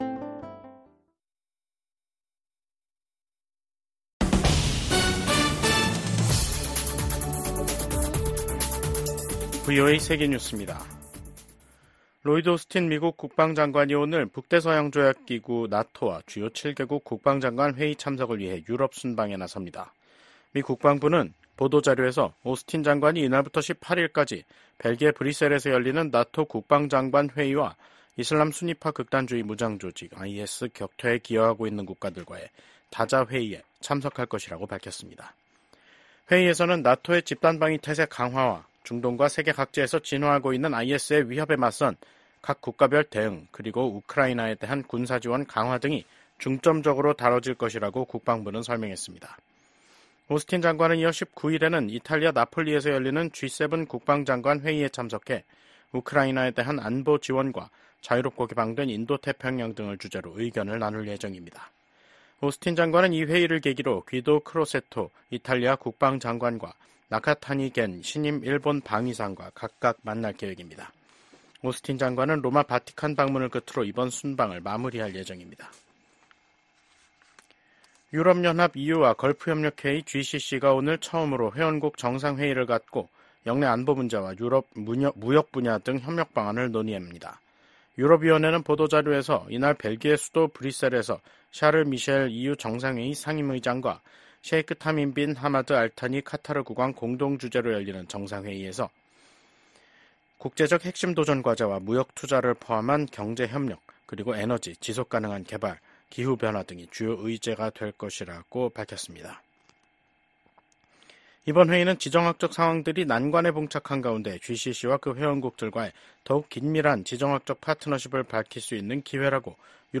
VOA 한국어 간판 뉴스 프로그램 '뉴스 투데이', 2024년 10월 16일 2부 방송입니다. 러시아의 방해로 해체된 유엔 대북 제재 감시의 공백을 메꾸기 위한 다국적 감시체제가 발족했습니다. 미국 정부는 북한이 한국과의 연결도로를 폭파하고 한국의 무인기 침투를 주장하며 군사적 대응 의사를 밝힌 데 대해 긴장 고조 행위를 멈출 것을 촉구했습니다.